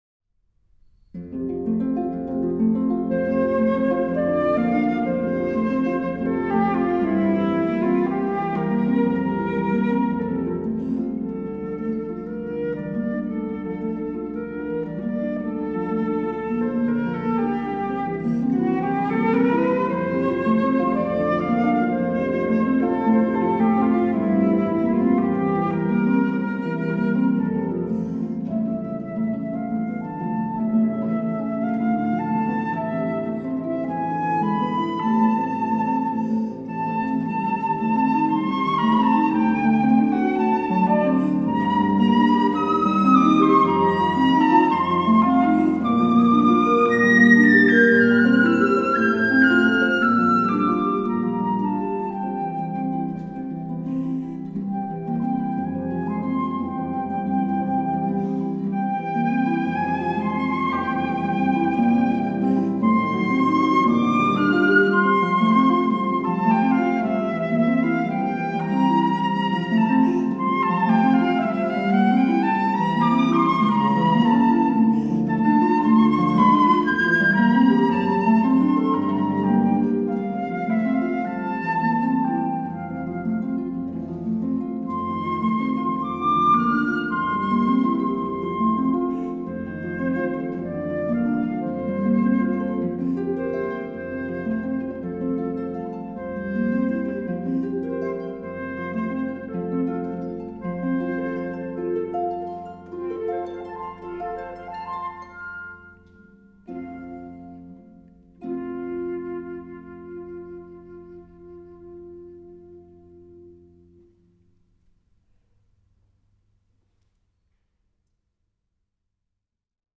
flute.
harp.